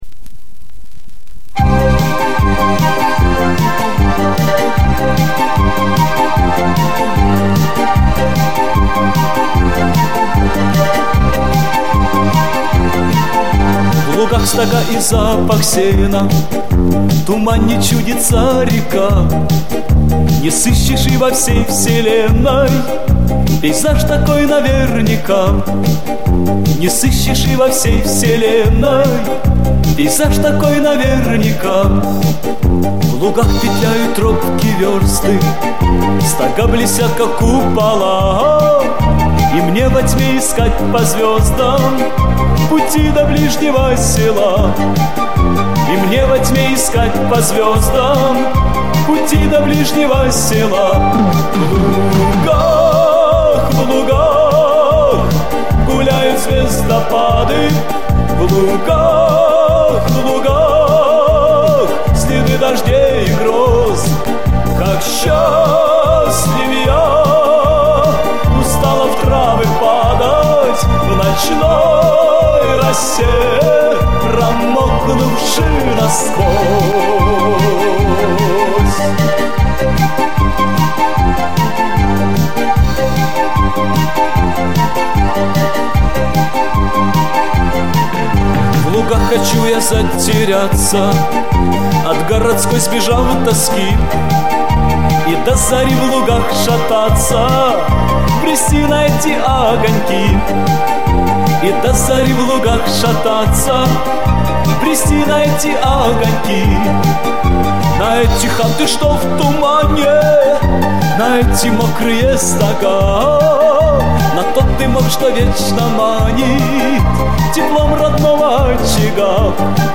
ОЦИФРОВКА С ПЛАСТИНКИ